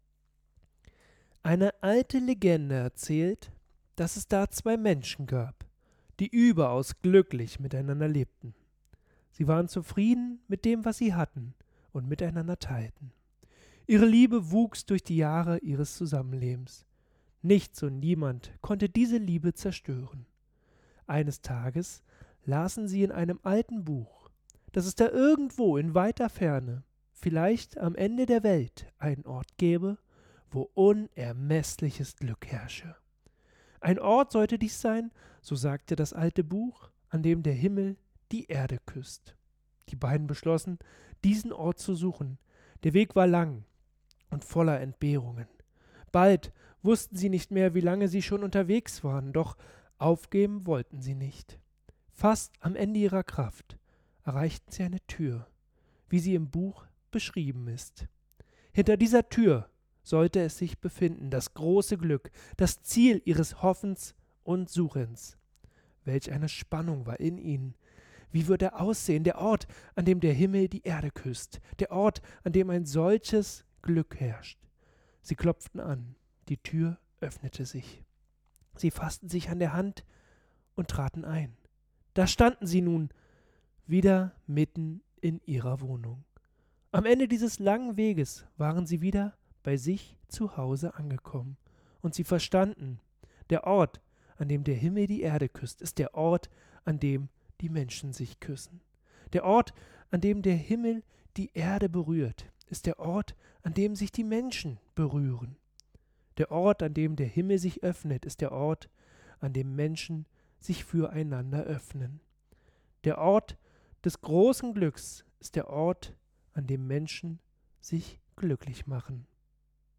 Redeproben:
Sprechprobe-lang.mp3